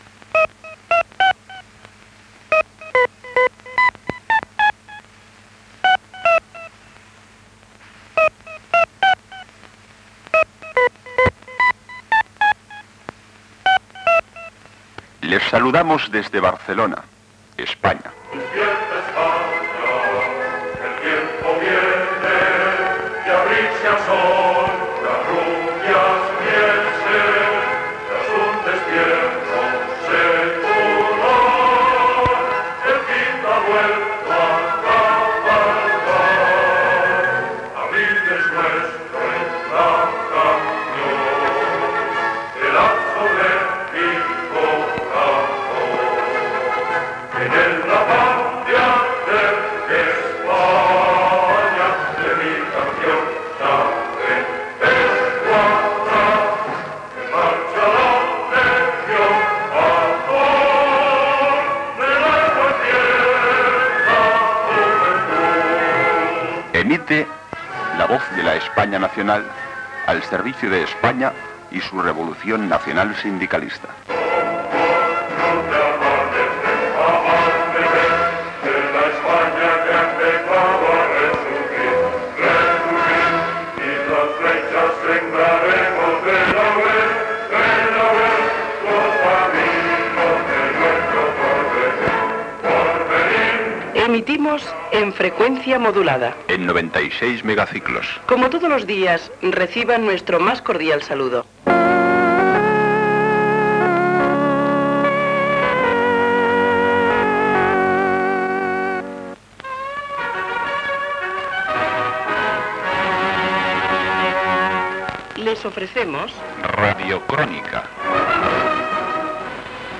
Sintonia, inici d'emissió i careta
FM